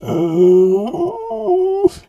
bear_pain_whimper_16.wav